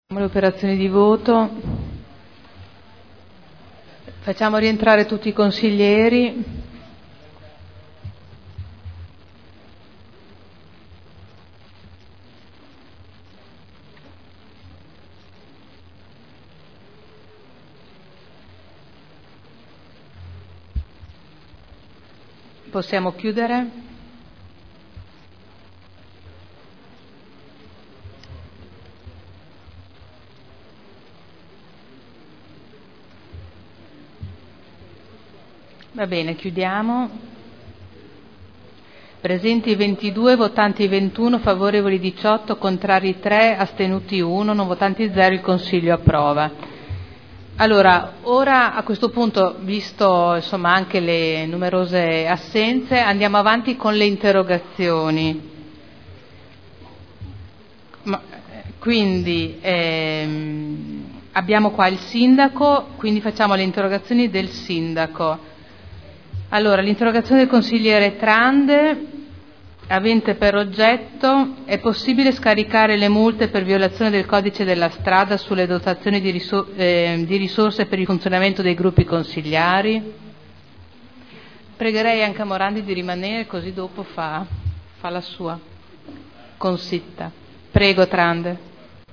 Seduta del 4/11/2010. Il Presidente Caterina Liotti mette ai voti l'ordine del Giorno (43080) presentato dai consiglieri Prampolini, Artioli, Rossi F., Garagnani, Dori, Rocco, Cotrino, Glorioso, Sala, Gorrieri, Caporioni, Urbelli, Guerzoni, Rimini, Morini, Cornia, Campioli, Pini, Trande (P.D.) avente per oggetto: “Ripristino delle tariffe postali agevolate per l’editoria” – Primo firmatario consigliere Prampolini (presentato il 12 aprile 2010)